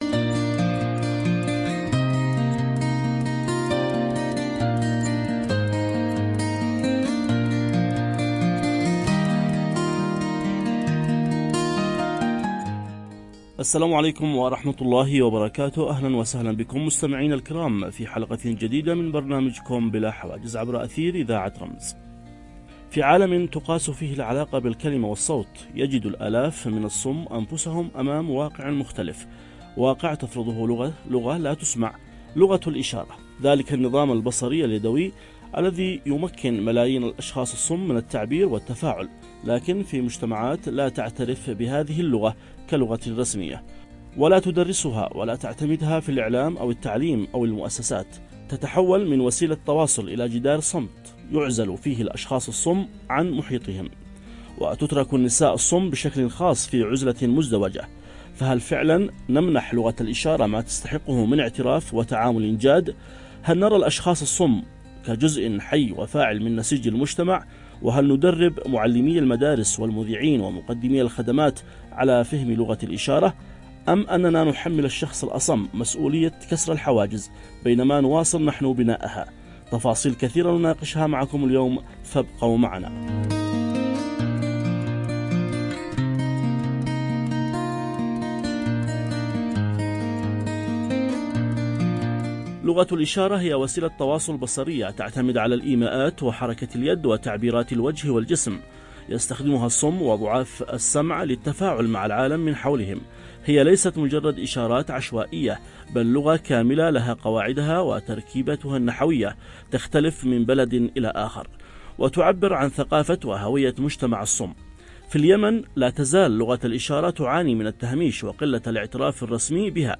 في حوار حول التحديات التي تواجه الصم في التواصل، وأهمية الاعتراف بلغة الإشارة كلغة رسمية ووسيلة للاندماج والتمكين.